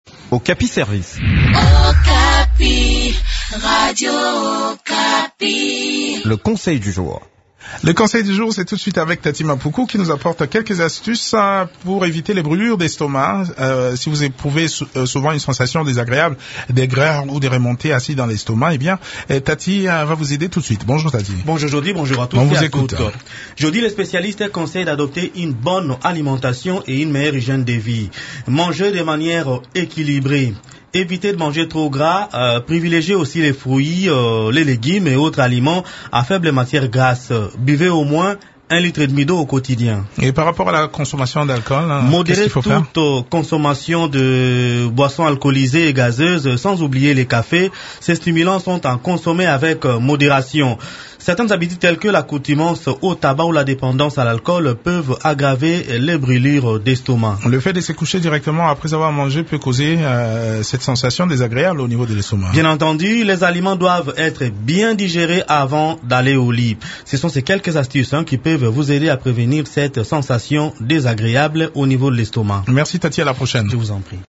Si vous éprouvez souvent une sensation désagréable d’aigreur ou de remontée acide dans l’estomac, découvrez les astuces qui peuvent vous aider à éviter ces sensations désagréables dans cette chronique